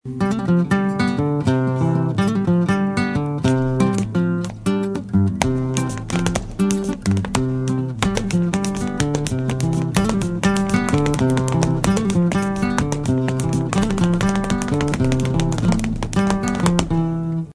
Sonidos de instrumentos Afroperuanos
"Guitarra" y "zapateo" Afroperuano
zapateo.mp3